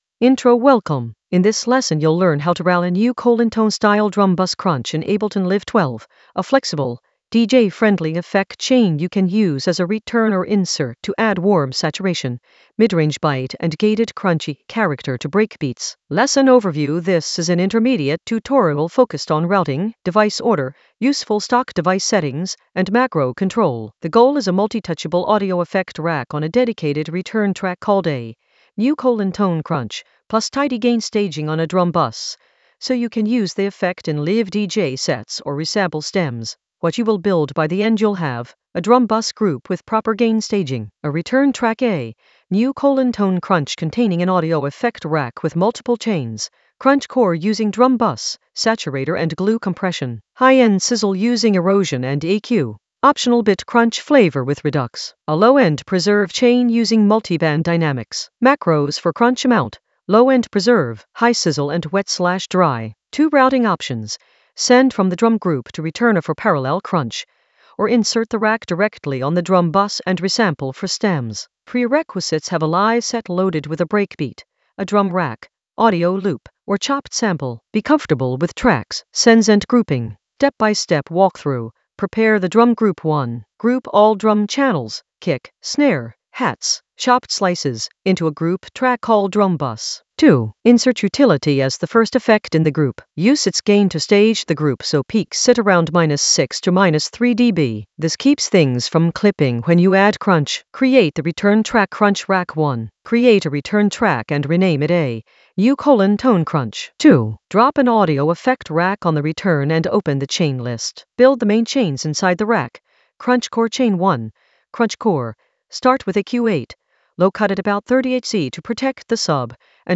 An AI-generated intermediate Ableton lesson focused on Route a Nu:Tone drum bus crunch in Ableton Live 12 for breakbeat science in the DJ Tools area of drum and bass production.
Narrated lesson audio
The voice track includes the tutorial plus extra teacher commentary.